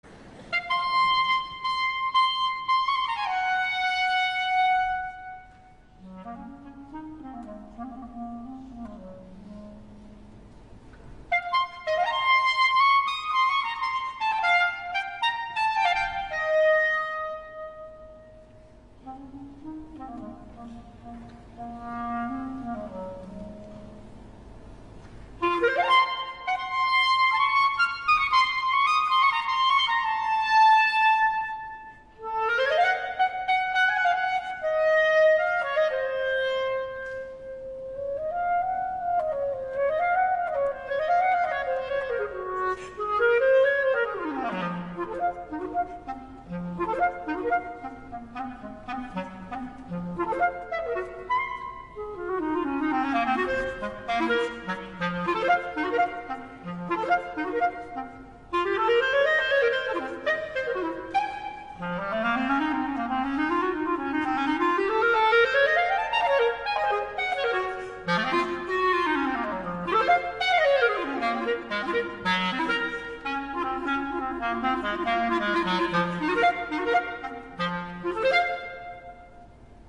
Viento Madera
CLARINETE
Clarinetto.mp3